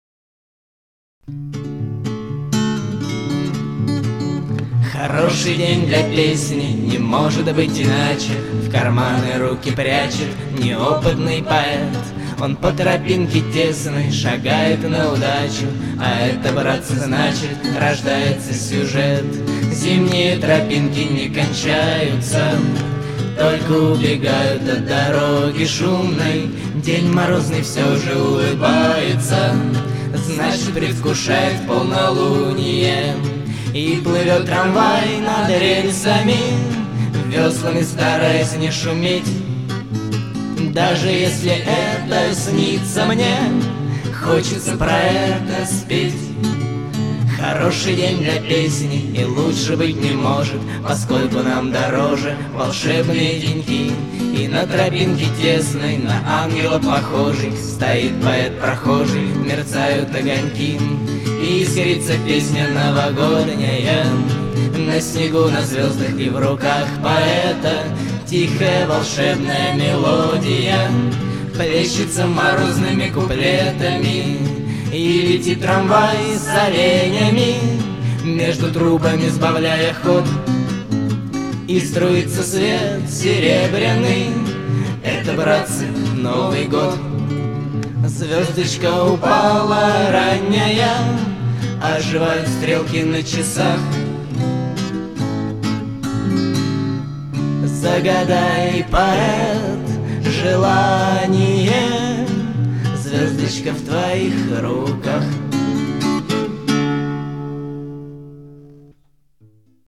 флейта